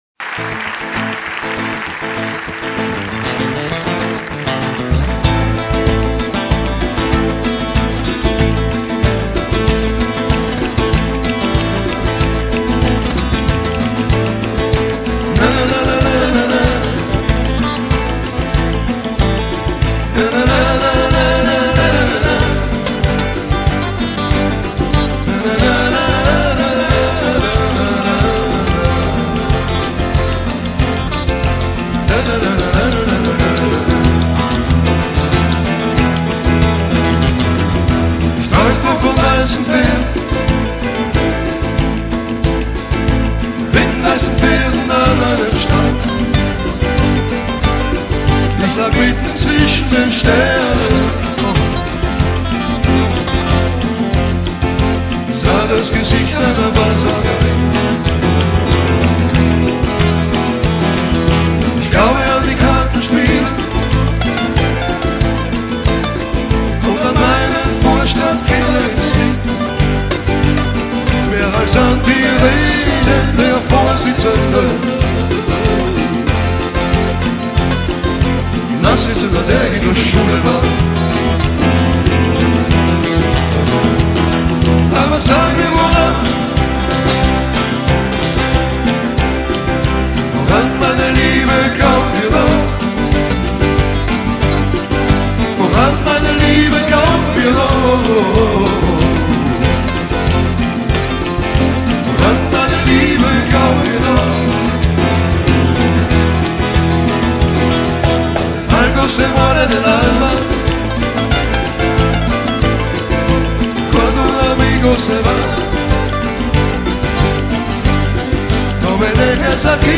ACORDEON - ÓRGÃO
Folclore Austríaco/Tirolês